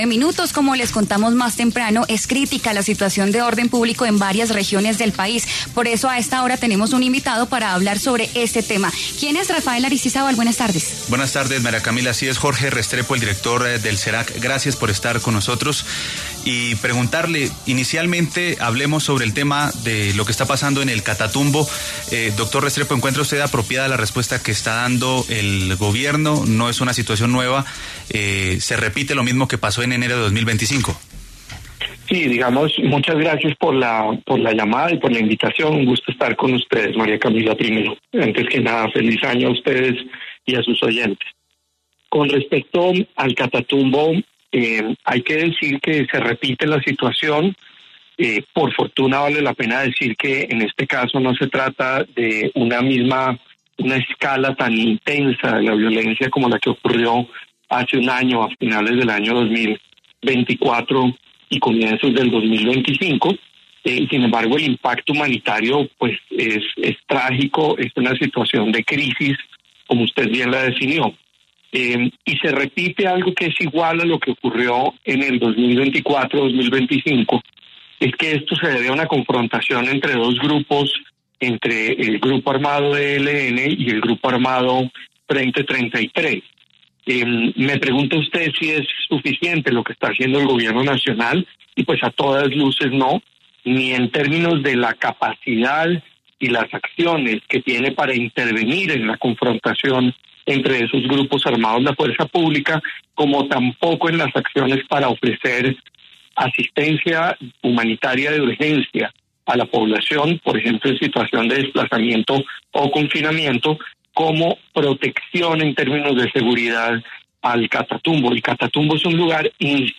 En entrevista con W Radio